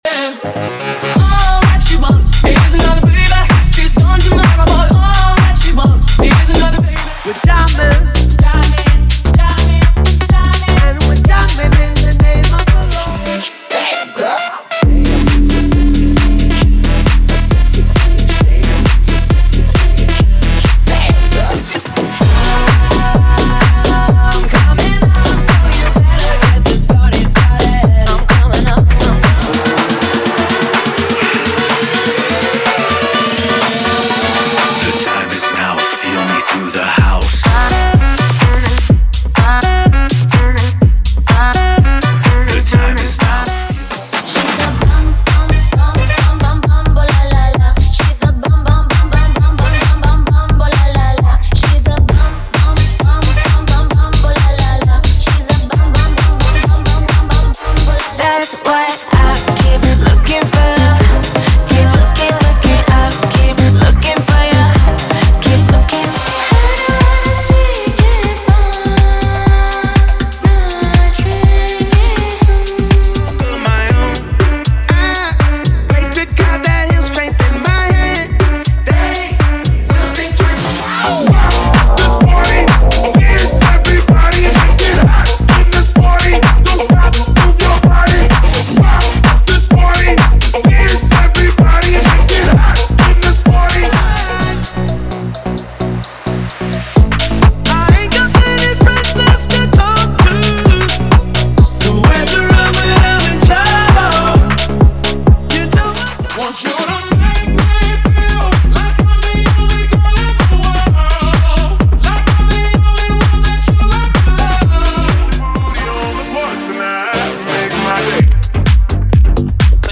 Sem Vinhetas